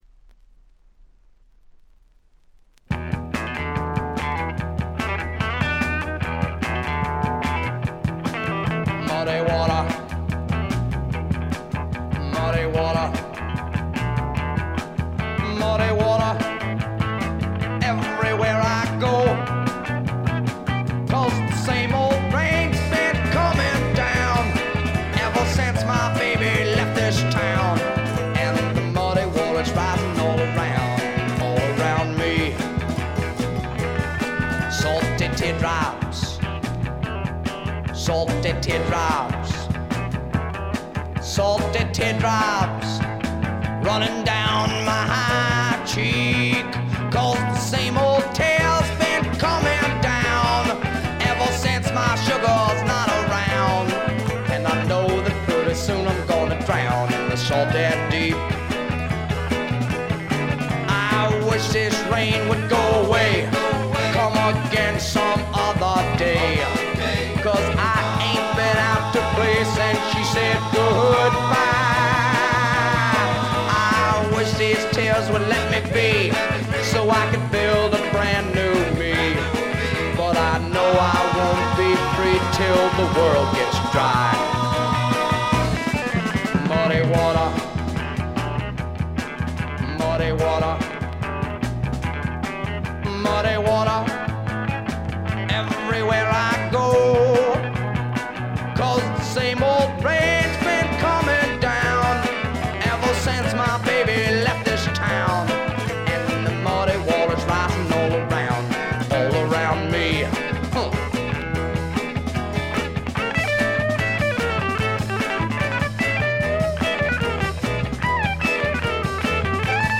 ほとんどノイズ感無し。
試聴曲は現品からの取り込み音源です。
Vocals, Guitar